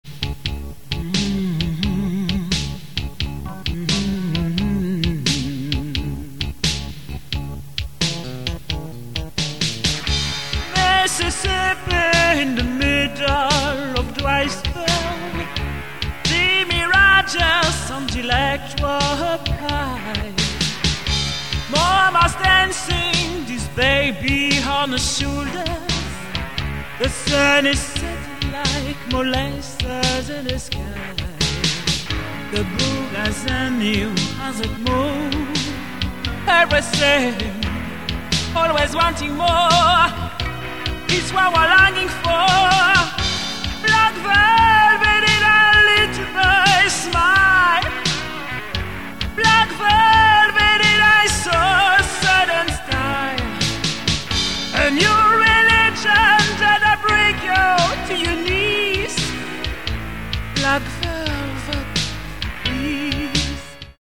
EXTRAIT PIANO-BAR